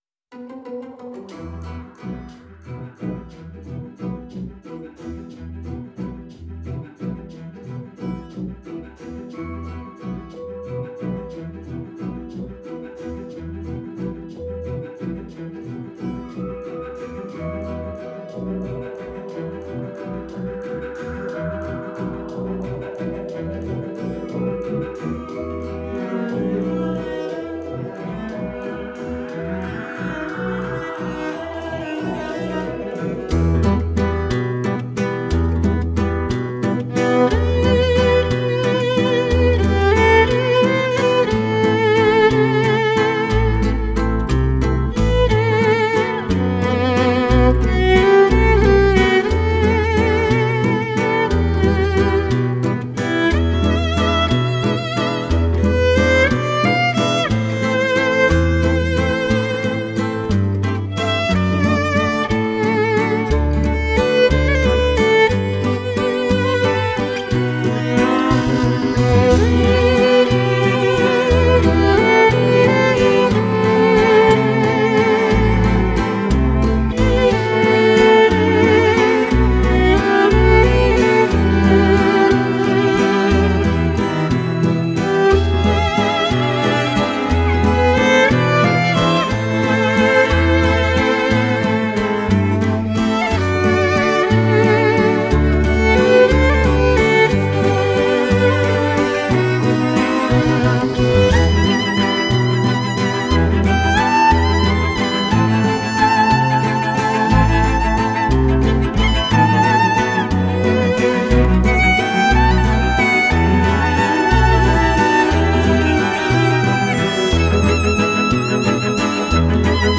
موسیقی بیکلام ویولون